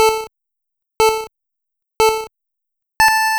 RaceCountdown.wav